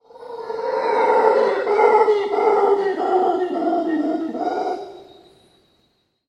Ревун вопит